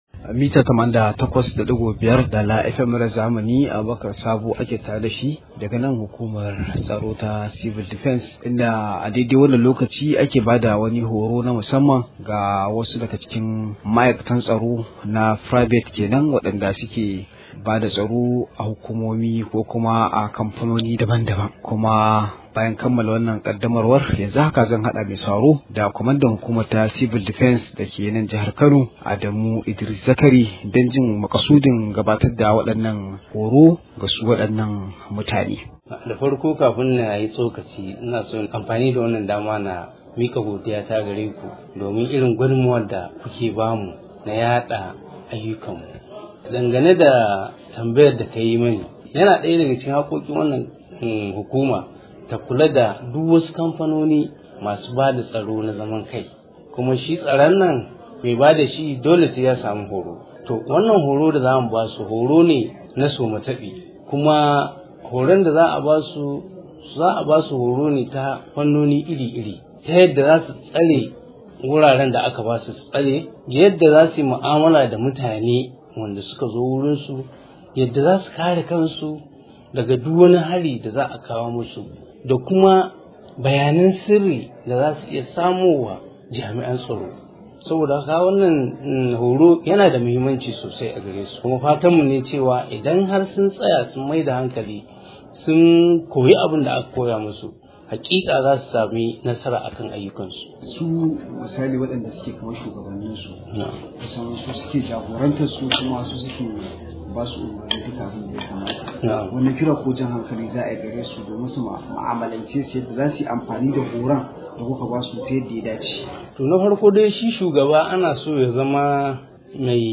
Rahoto:  Jami’an tsaro masu zaman kansu na bukatar horo – Civil Defence